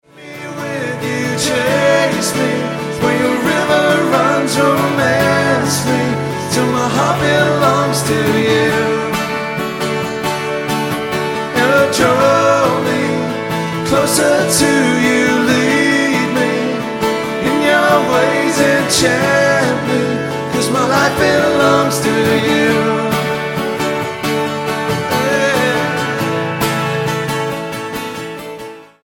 STYLE: Pop
minimal percussion and strings